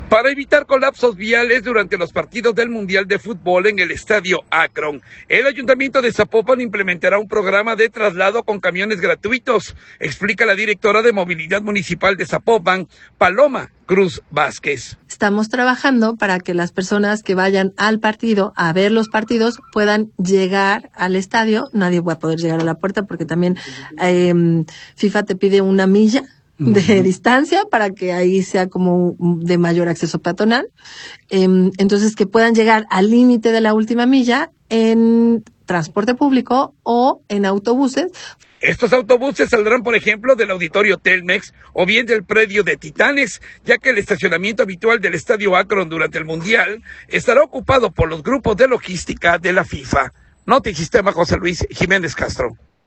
Para evitar colapsos viales durante los partidos del Mundial de Futbol en el Estadio Akron, el Ayuntamiento de Zapopan implementará un programa de traslado con camiones gratuitos, explica la directora de Movilidad de Zapopan, Paloma Cruz Vázquez.